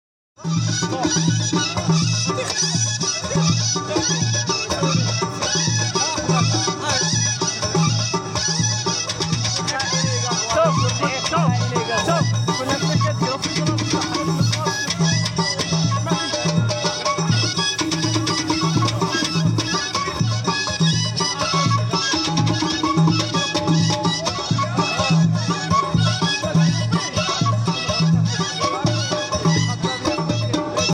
أحواش إحاحان بمهرجان إسك إداوتنان